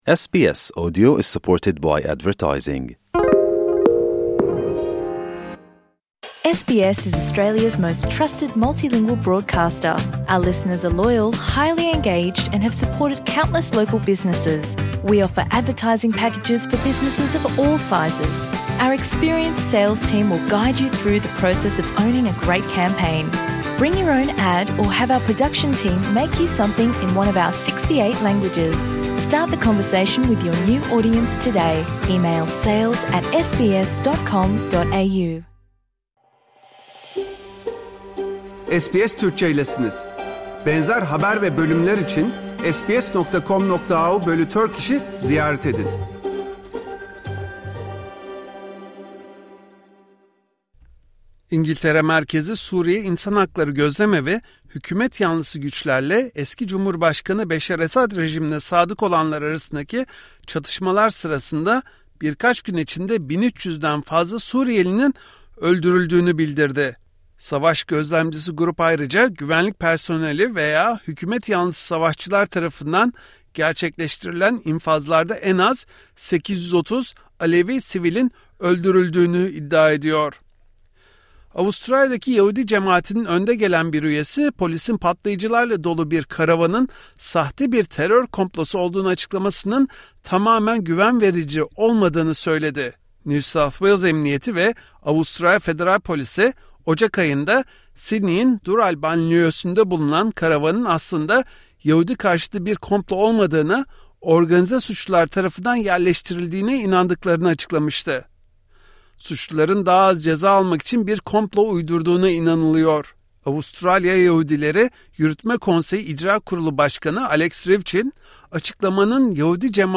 Avustralya, Türkiye ve dünyadan haftanın tüm gelişmeleri SBS Türkçe Haftaya Bakış bülteninde.